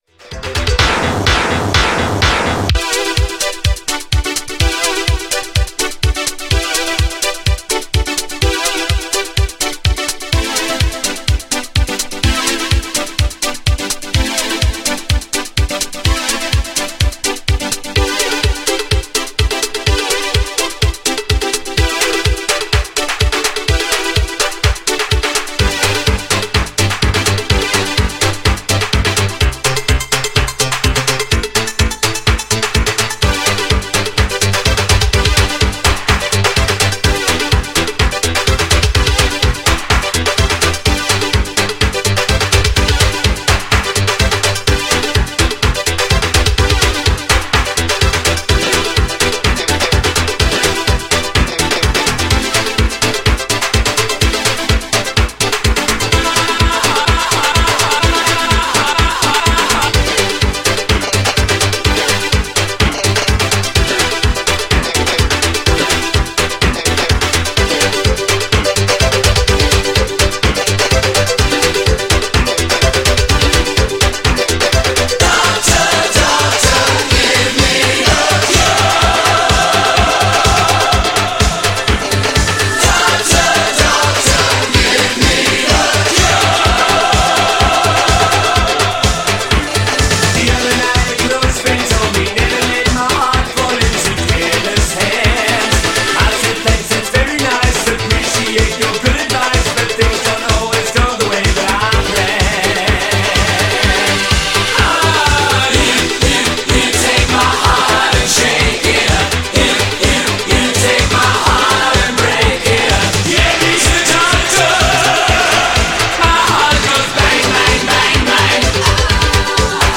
ややイタロテイストなアレンジが今聴いてもイケる!!
GENRE Dance Classic
BPM 126〜130BPM